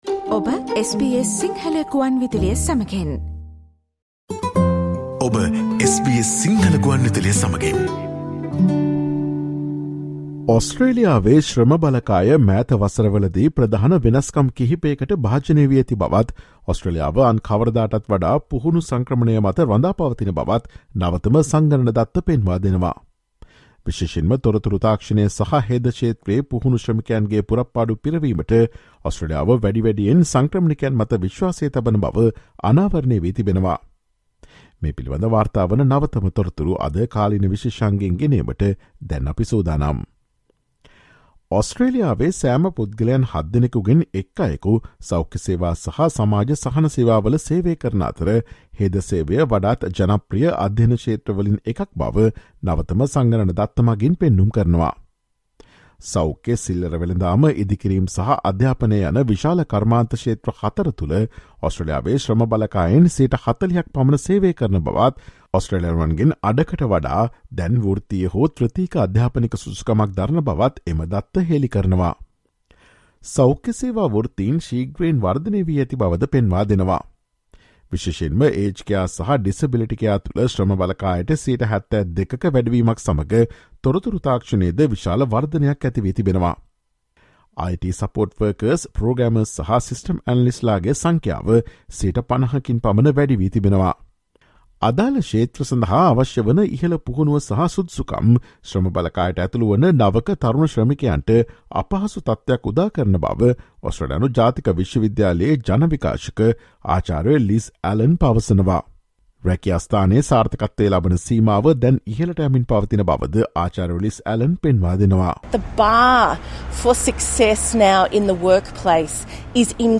New Census data shows Australia’s workforce has undergone some major changes in recent years and the country is more dependent on skilled migration than ever. Listen to the SBS Sinhala Radio's current affairs feature broadcast on Thursday 13 October.